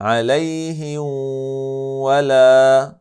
Det ska uttalas på följande sätt: